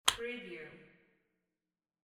Handbag Lock Wav Sound Effect #7
Description: The sound of a handbag lock snapping open or close (a single click)
Properties: 48.000 kHz 16-bit Stereo
Keywords: handbag, purse, bag, lock, locking, unlocking, snap, click, open, opening, close, closing, shut, shutting, latch, unlatch
handbag-lock-preview-7.mp3